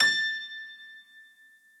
b_piano1_v100l1o7a.ogg